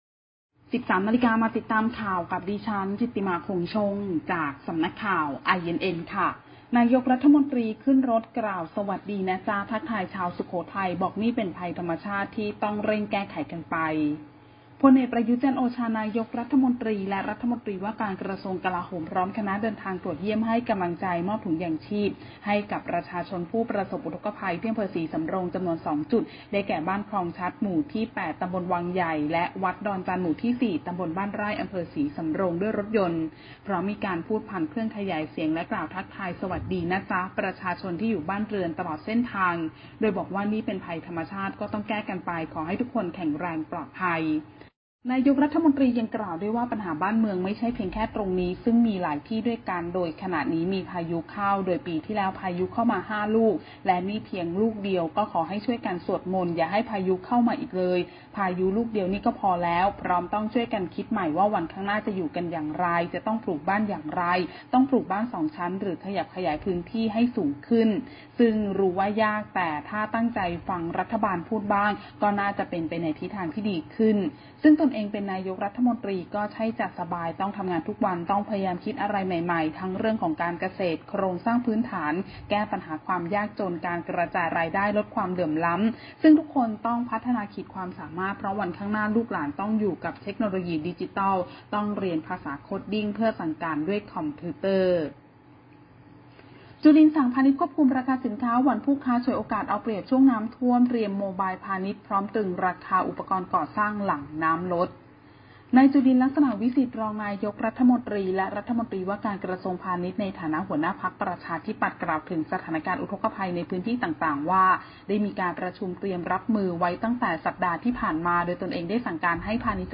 ข่าวต้นชั่วโมง 13.00 น.
พล.อ.ประยุทธ์ จันทร์โอชา นายกรัฐมนตรีและรัฐมนตรีว่าการกระทรวงกลาโหม พร้อมคณะเดินทางตรวจเยี่ยมให้กำลังใจและมอบถุงยังชีพให้แก่ประชาชนผู้ประสบอุทกภัย อ.ศรีสำโรง จำนวน 2 จุด ได้แก่ บ้านคลองชัด หมู่ ที่ 8 ต.วังใหญ่และวัดดอนจันทร์ หมู่ที่ 4 ต.บ้านไร่ อ.ศรีสำโรง จ.สุโขทัย ด้วยรถยนต์ พร้อมมีการพูดคุยผ่านเครื่องขยายเสียงและทักทายสวัสดี “นะจ๊ะ”ประชาชนที่อยู่ตามบ้านเรือนตลอดเส้นทาง โดยบอกว่า นี่เป็นภัยธรรมชาติ ก็ต้องแก้กันไป ขอให้ทุกคนแข็งแรง ปลอดภัย